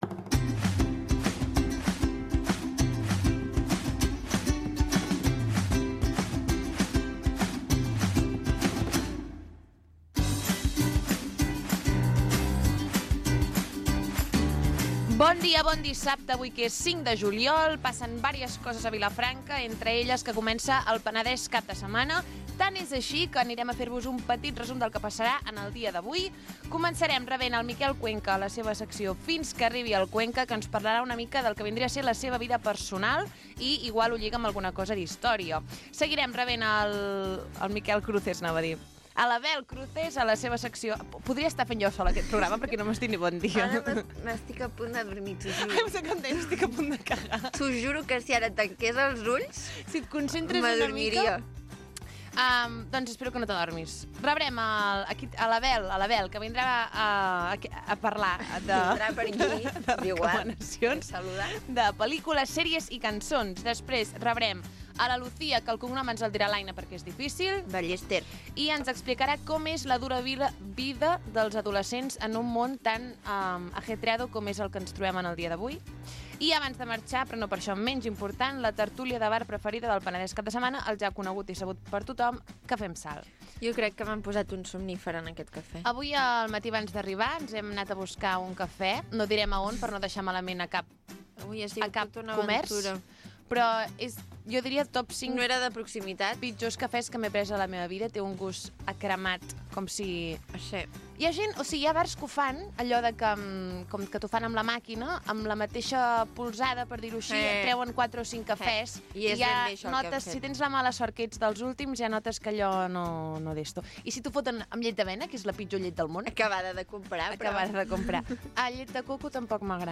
El magazín del cap de setmana